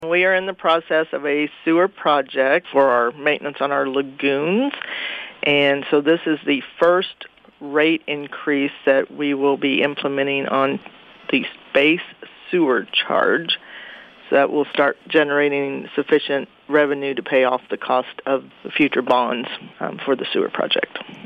City Administrator Jeanette Dobson explains.